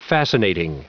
Prononciation du mot fascinating en anglais (fichier audio)
Prononciation du mot : fascinating